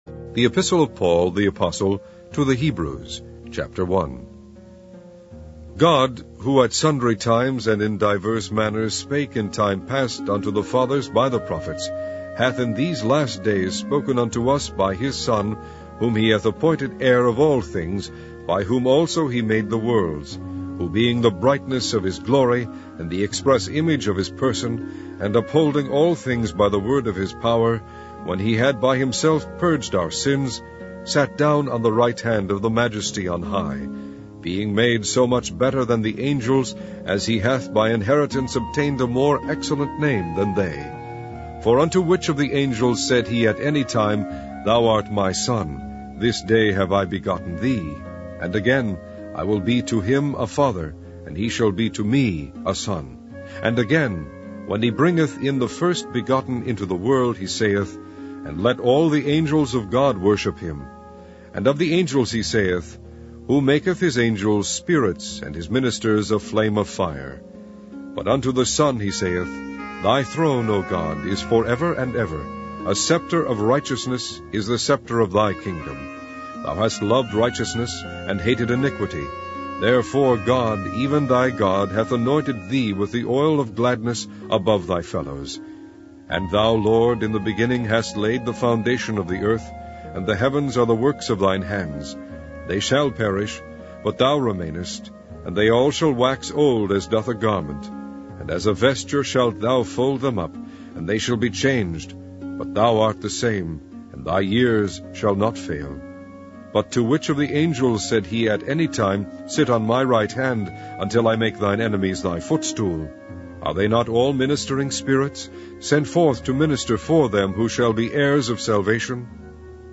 Online Audio Bible - King James Version - Hebrews